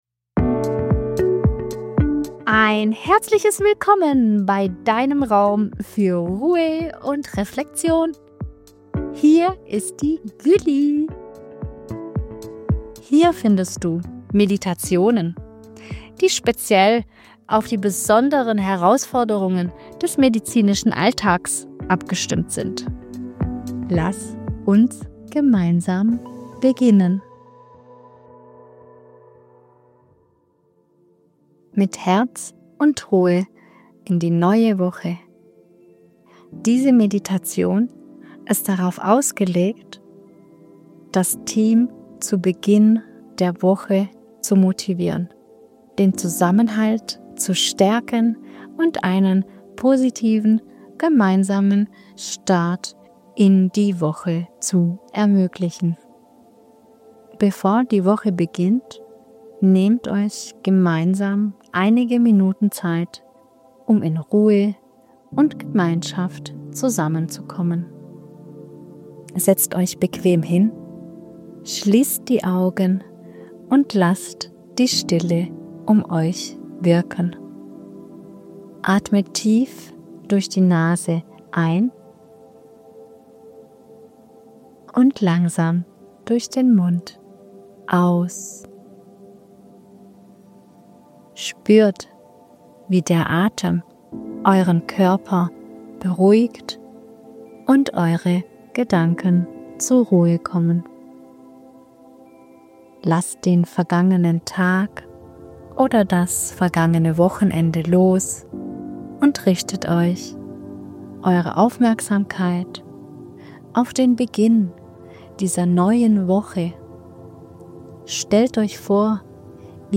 (00:00:04) Gemeinsame Meditation zur Wochenmotivation